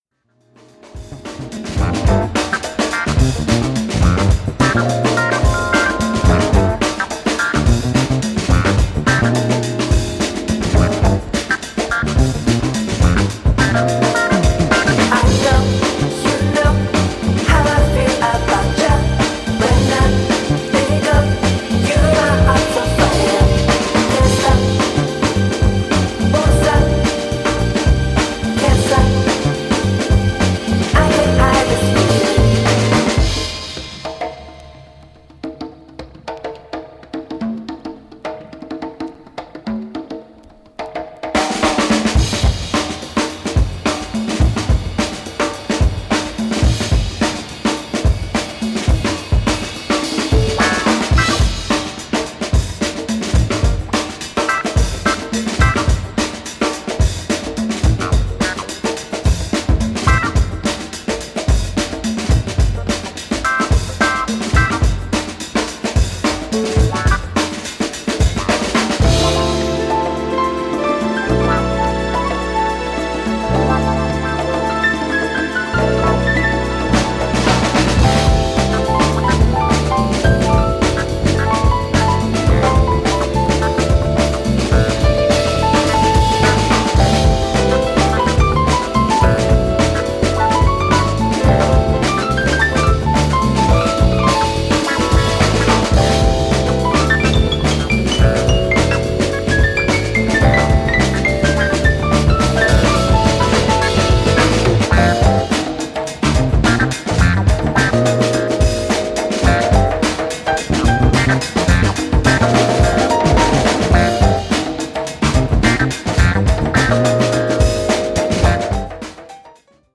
5 track crossover banger for all scenes & vibes.
piano
Track 5 Disco Funk